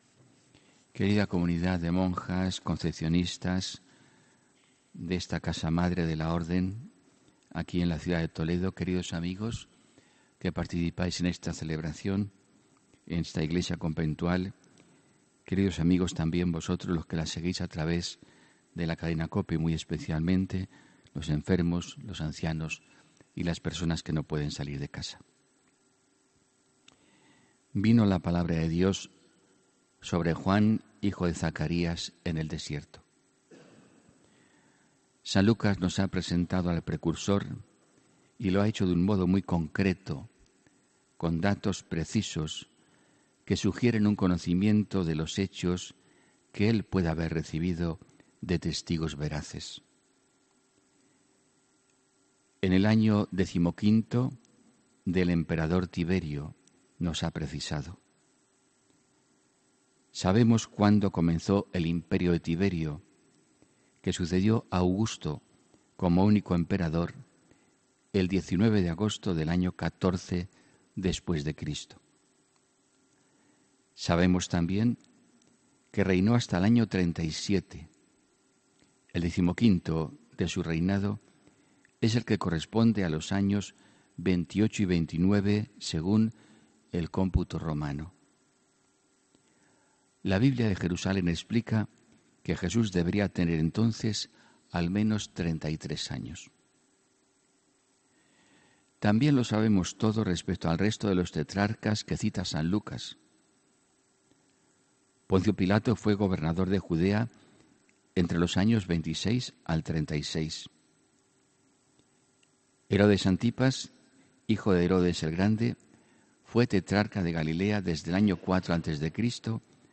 HOMILÍA 9 DICIEMBRE 2018